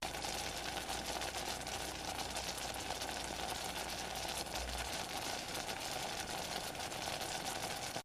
Cooking, Food Boils & Sizzles 1